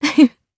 Seria-Vox-Laugh_jp.wav